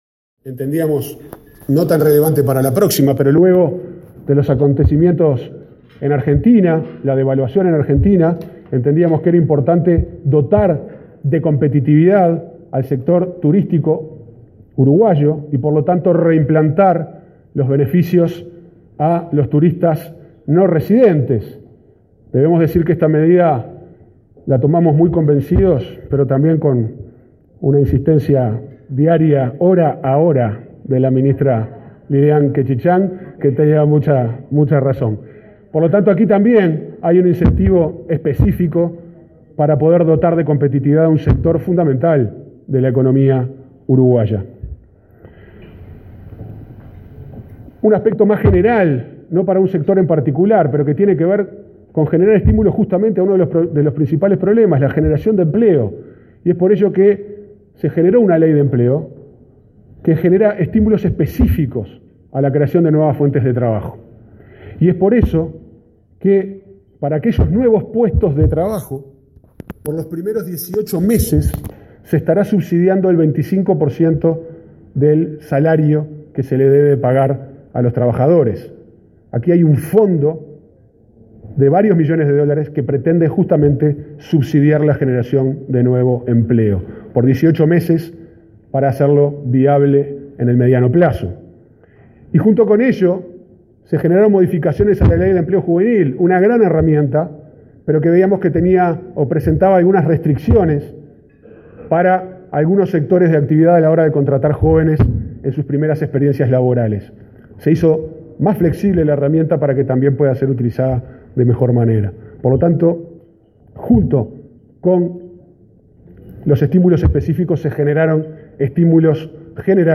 El subsecretario de Economía, Pablo Ferreri, enumeró este martes en “Somos Uruguay” las acciones que dispuso el Gobierno para incentivar la inversión y generar empleos. En ese marco, destacó los beneficios fiscales del decreto de la Comap que estimula el empleo y el decreto 329 de 2016 sobre grandes obras de edilicias.